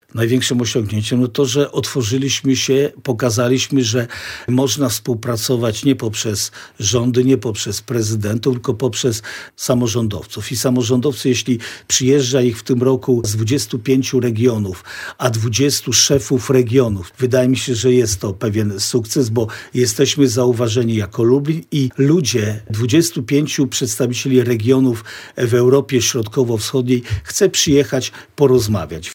– W tym roku przyjmujemy na kongresie także jeden z regionów Mołdawii, regularnie zapraszamy także przedstawicieli Ukrainy – mówi marszałek województwa lubelskiego Jarosław Stawiarski, który był gościem porannej rozmowy w Radiu Lublin.